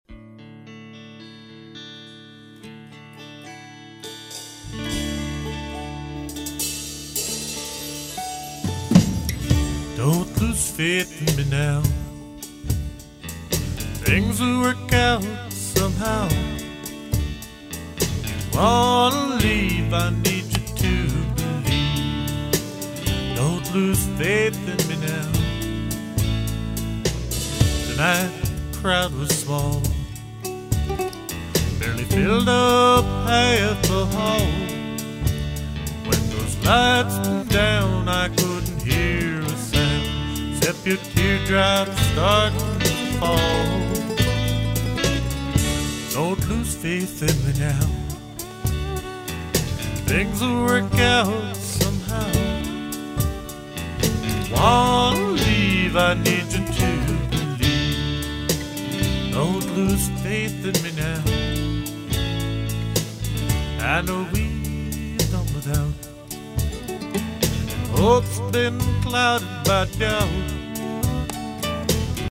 mp3 / S / Alt Country